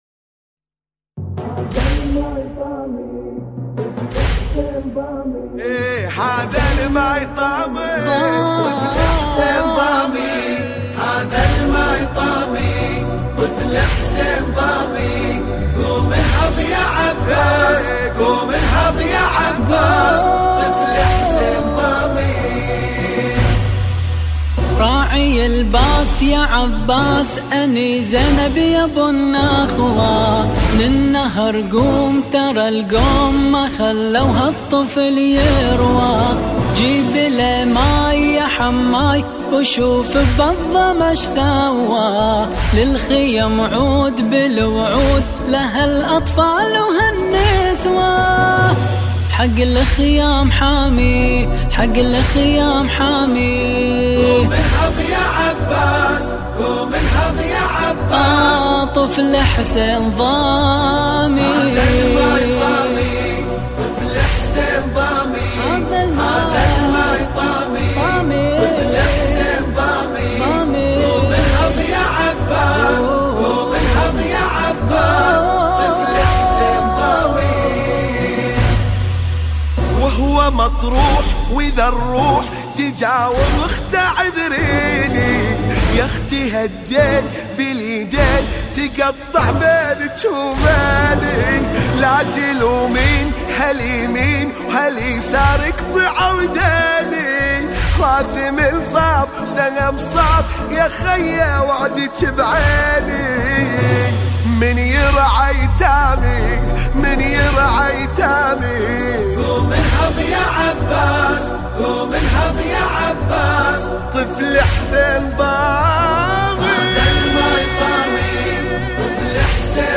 مراثي أبو الفضل العباس (ع)